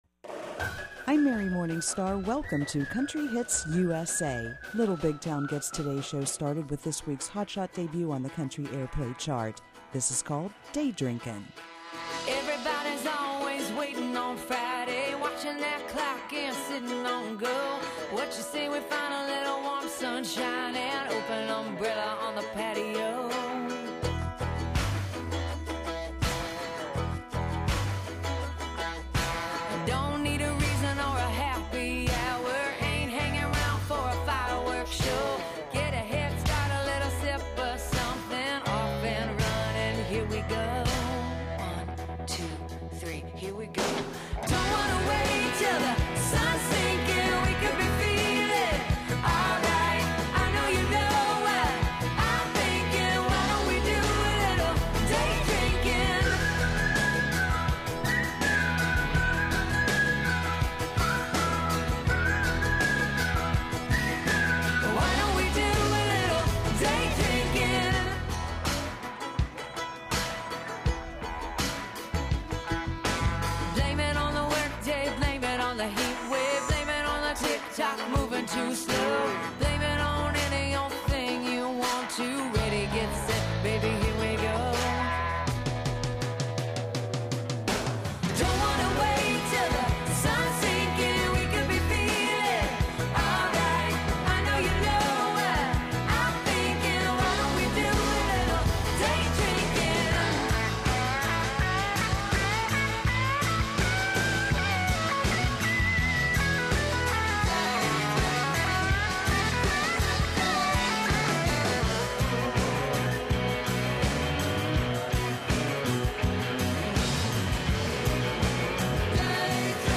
Country music hits